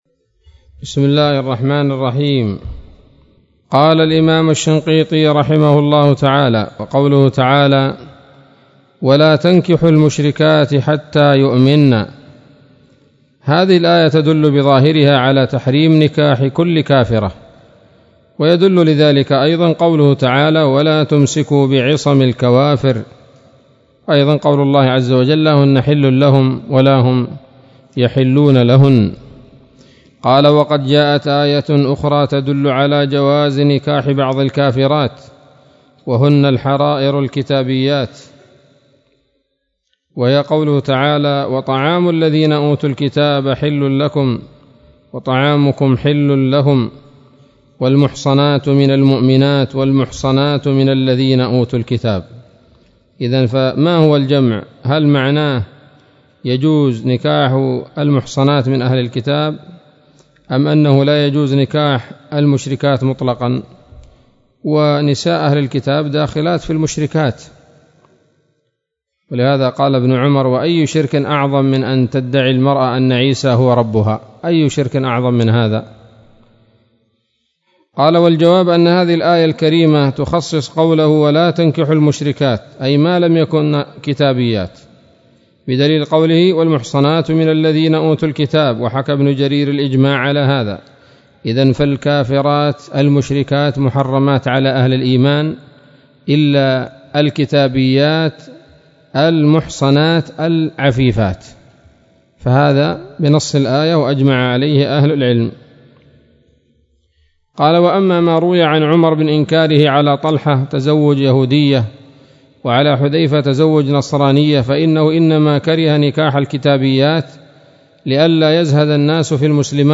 الدرس التاسع عشر من دفع إيهام الاضطراب عن آيات الكتاب